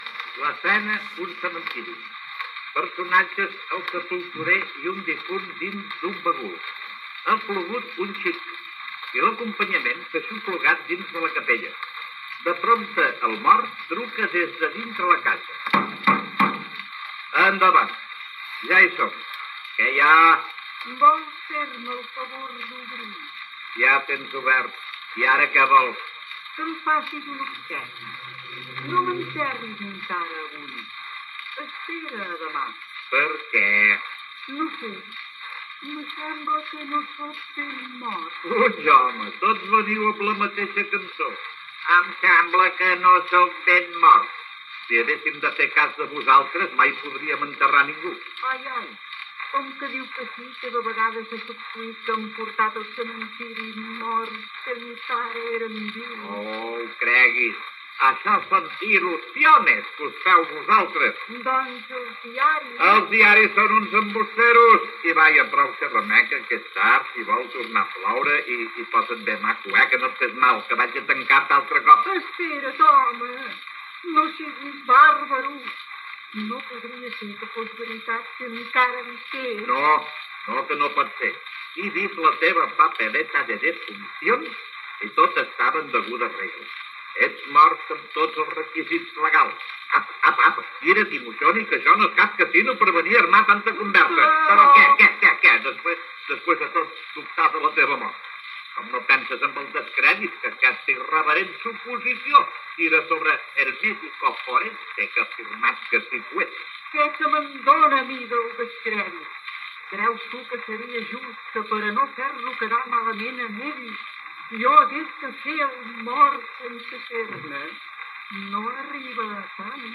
Enregistrament discogràfic de la ficció "El Sepulturer Filòsof - Diàleg inverossimil primera part".
Entreteniment
Extret del disc de pedra Parlophon catàleg B 25564.